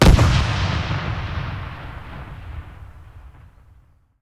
The explosion also now selects from 4 different exploding sounds at random to provide some variation.
Explo4.wav